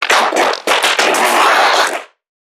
NPC_Creatures_Vocalisations_Infected [54].wav